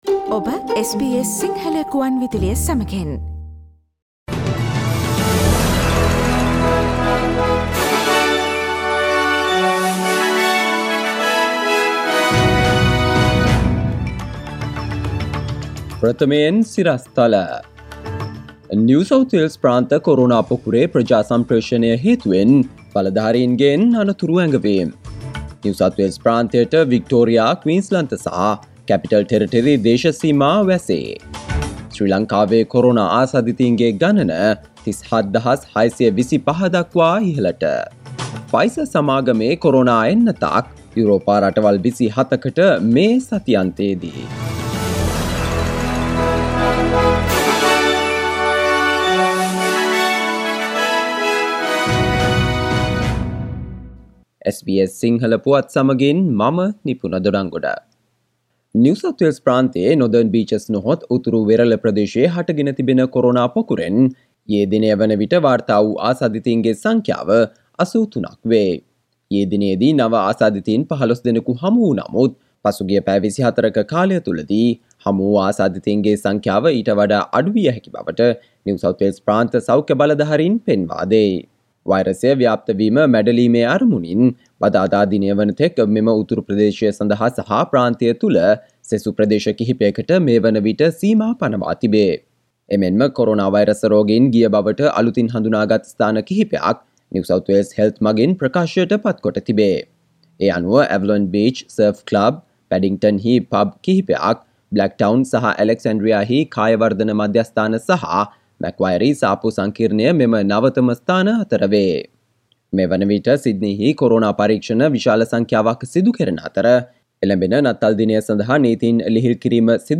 Today’s news bulletin of SBS Sinhala radio – Tuesday 22 December 2020